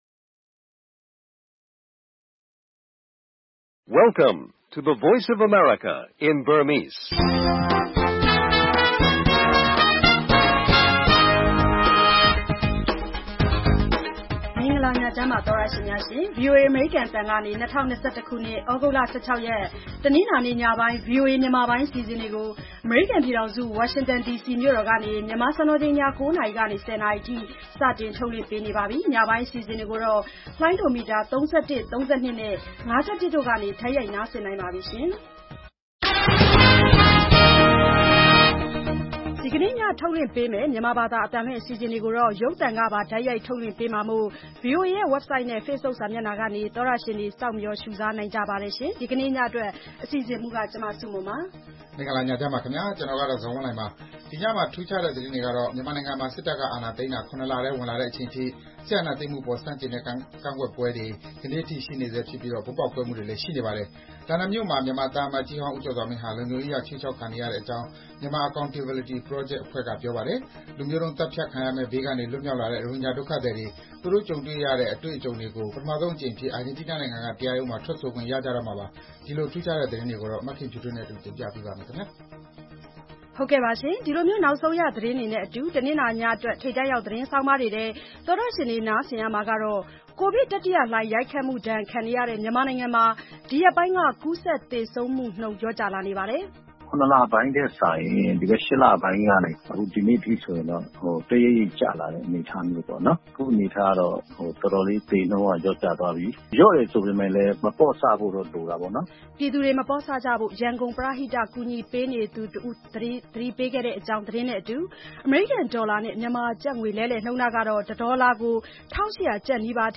VOA ရေဒီယိုညပိုင်း ၉း၀၀-၁၀း၀၀ တိုက်ရိုက်ထုတ်လွှင့်မှု(သြဂုတ်၁၆၊၂၀၂၁)